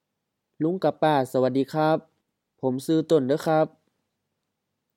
BCF04 Introducing oneself to others — Dialogue A
สะหวัดดี sa-wat-di: M-M-M สวัสดี polite greeting: hello, good day, good morning, good evening etc.
คับ khap H ครับ male politeness particle